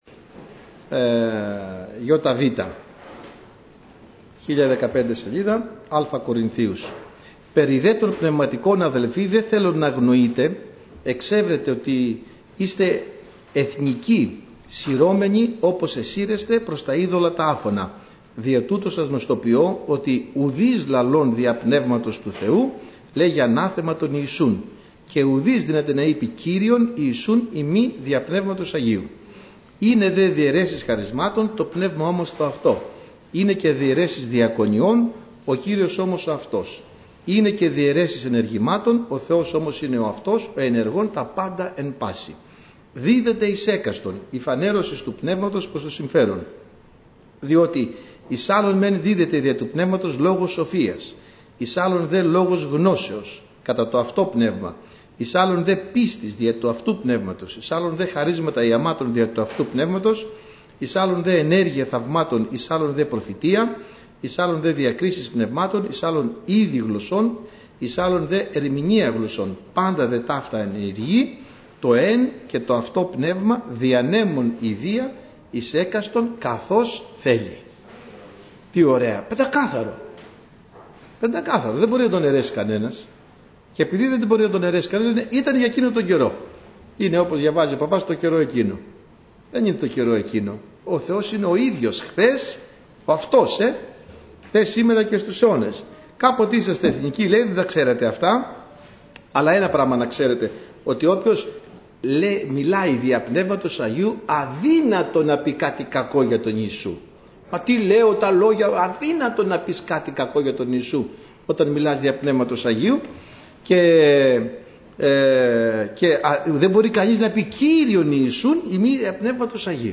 Ομιλητής: Διάφοροι Ομιλητές
Σειρά: Κηρύγματα